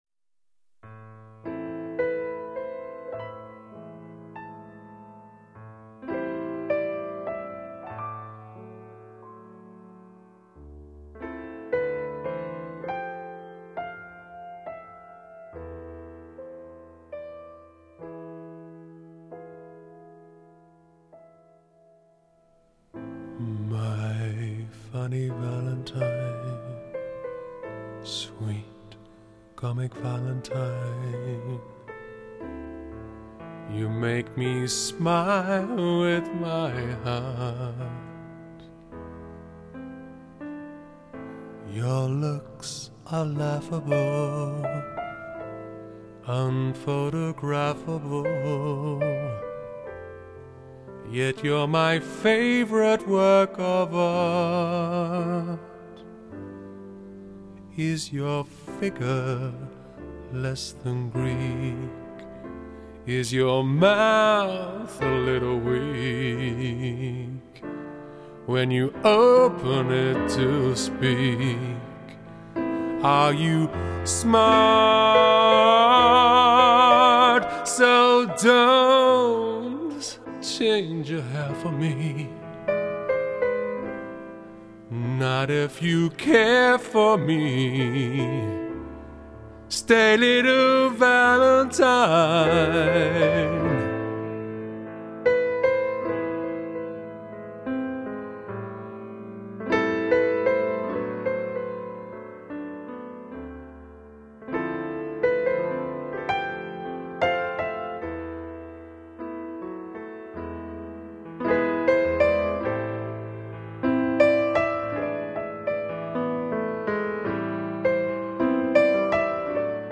classic love songs